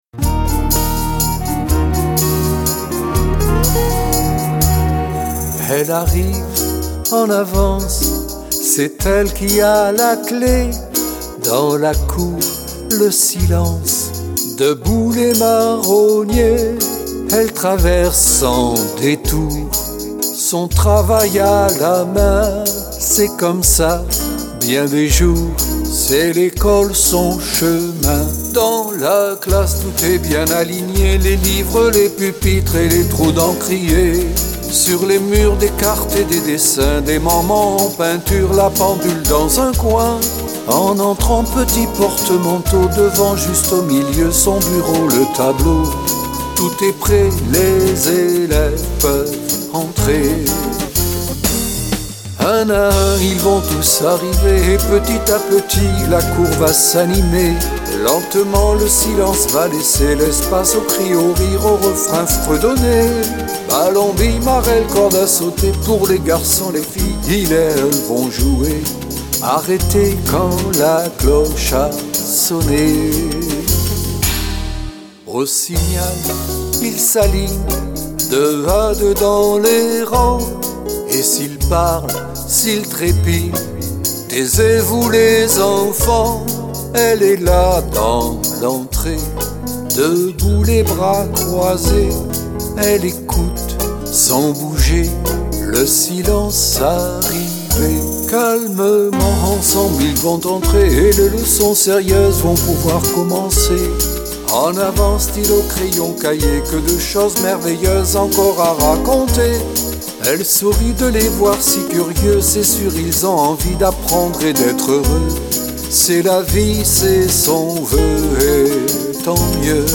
version intégrale chantée